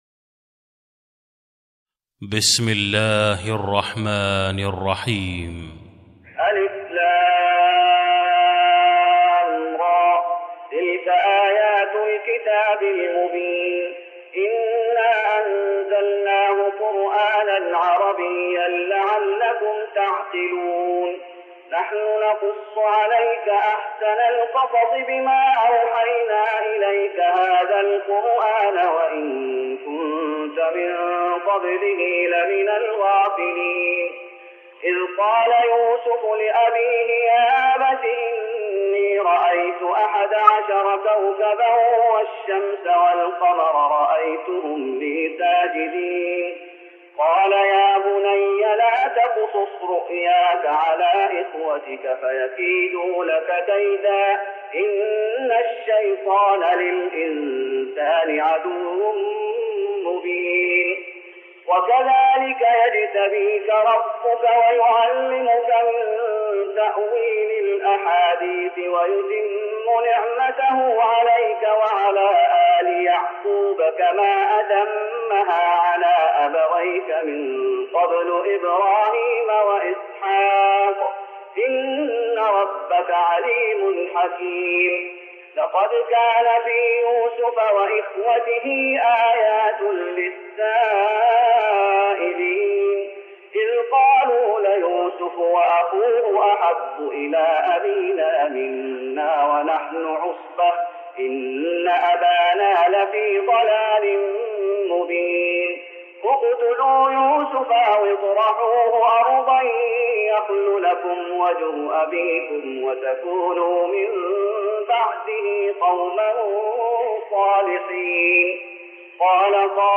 تراويح رمضان 1414هـ من سورة يوسف (1-53) Taraweeh Ramadan 1414H from Surah Yusuf > تراويح الشيخ محمد أيوب بالنبوي 1414 🕌 > التراويح - تلاوات الحرمين